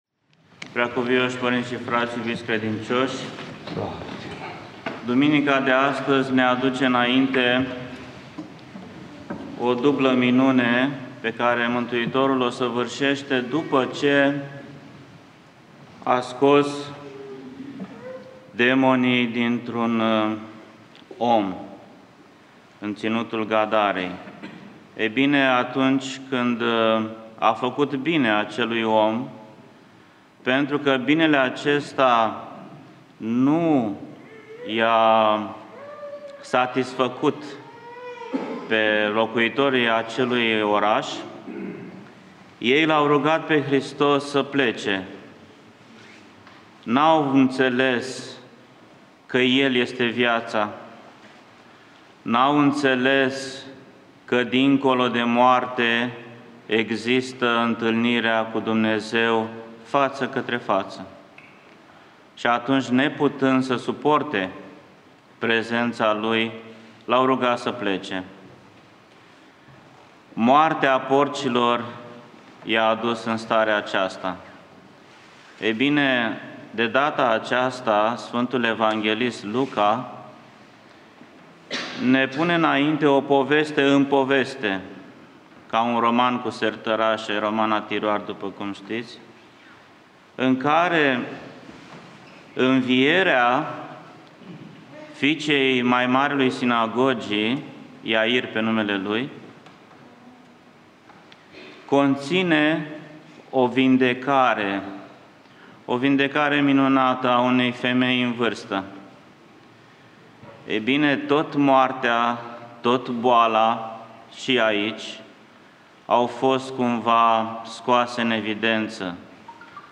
Arhivă de predici la Duminica a XXIV-a după Rusalii - învierea fiicei lui Iair / ortodoxradio